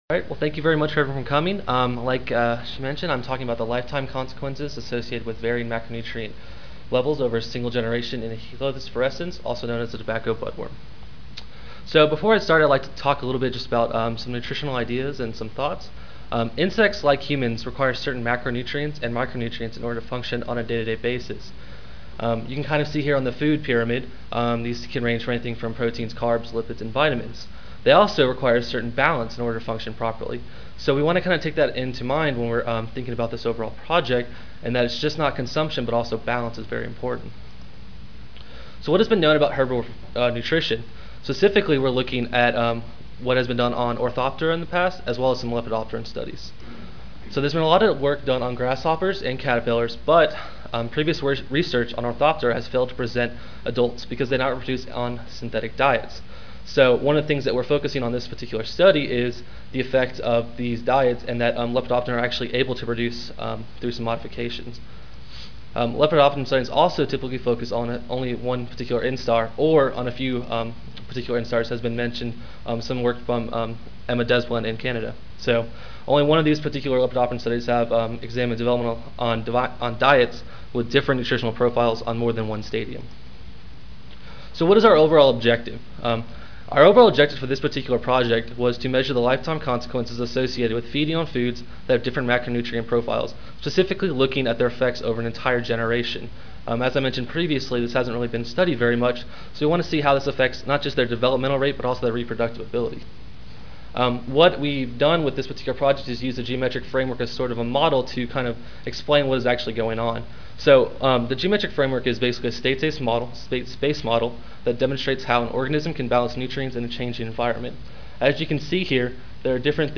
Room A18, First Floor (Reno-Sparks Convention Center)